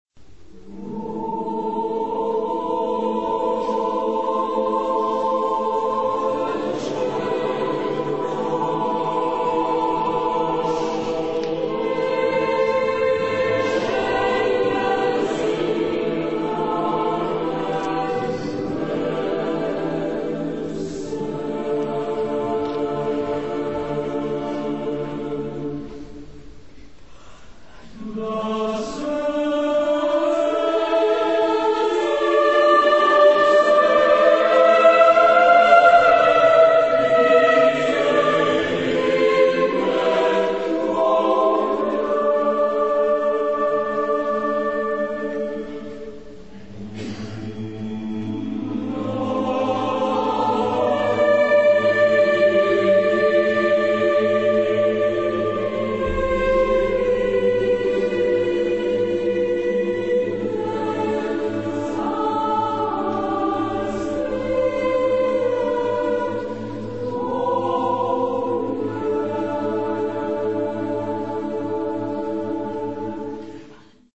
Genre-Style-Form: Sacred ; Neo-baroque ; Chorale
Mood of the piece: majestic ; calm
Type of Choir: SSAATTBB  (8 mixed voices )
Tonality: F sharp dorian ; E dorian ; A aeolian